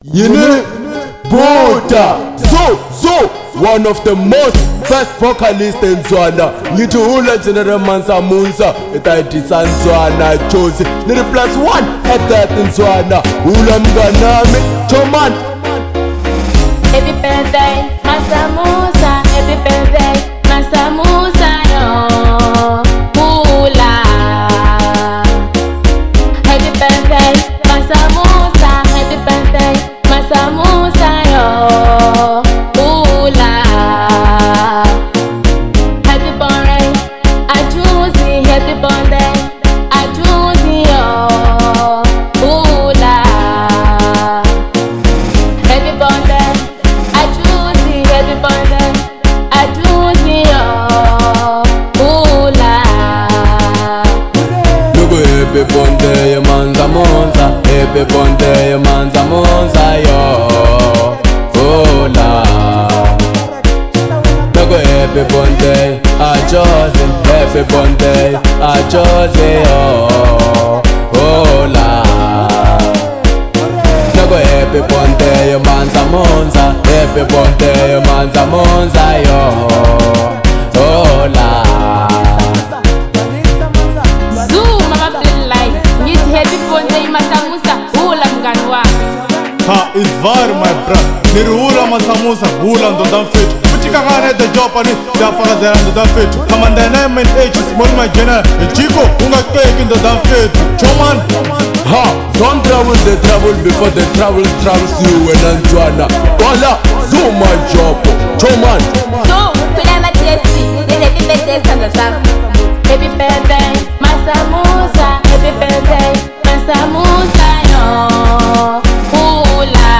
05:32 Genre : Marrabenta Size